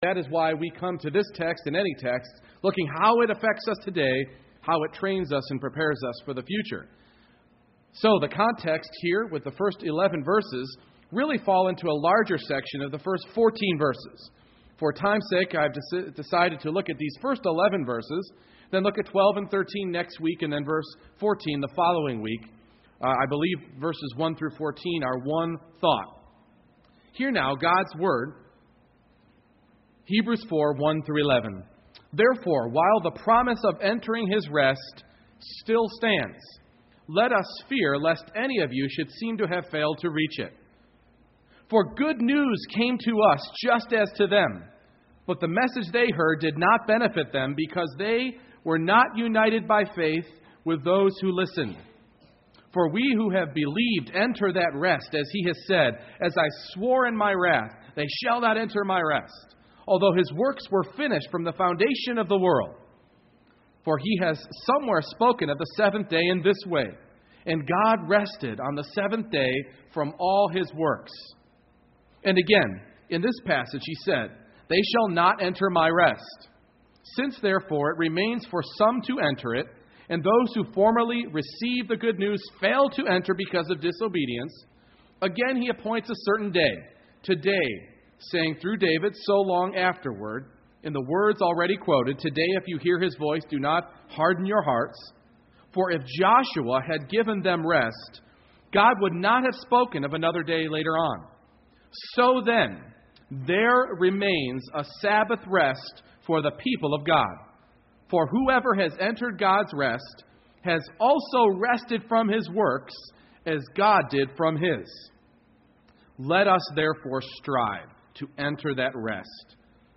Hebrews 4:1-11 Service Type: Morning Worship God continues to promise rest for His people.